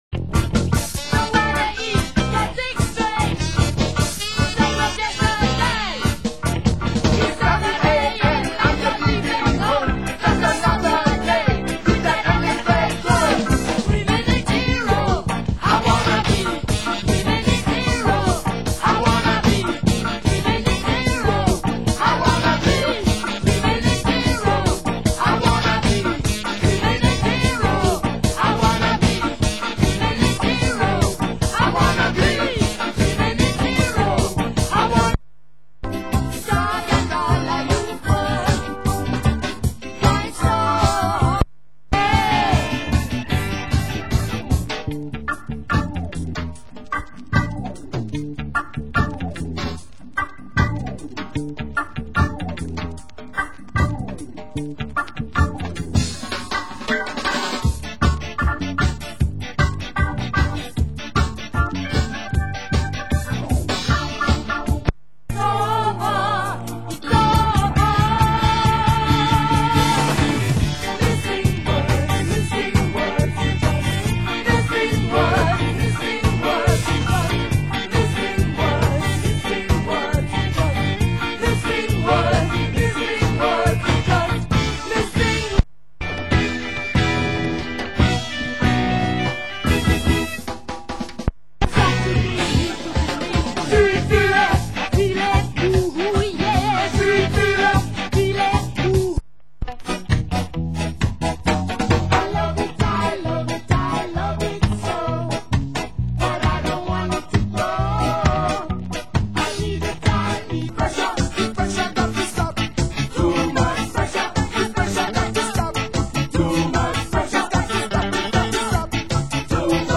Genre: Reggae